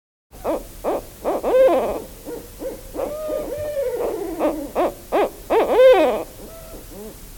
Barred Owl
(Strix varia)
Northern-Barred-Owl-edit.mp3